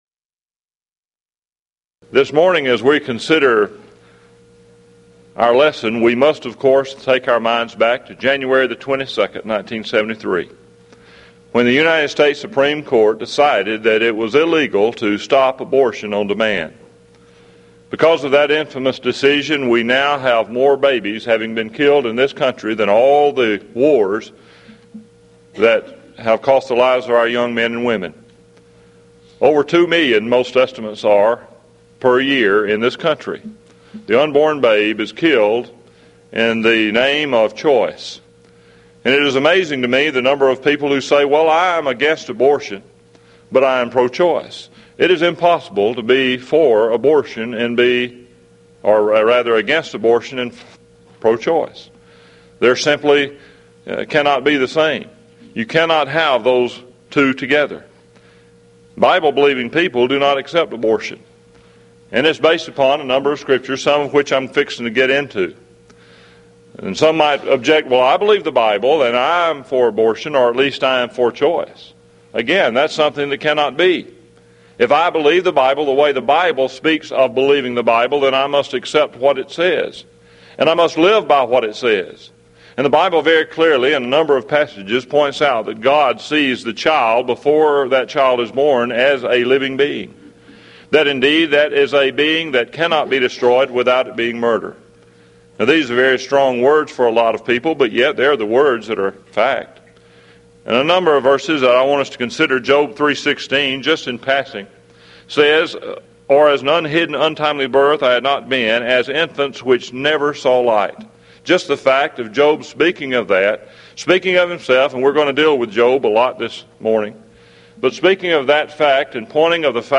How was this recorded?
Event: 1995 Mid-West Lectures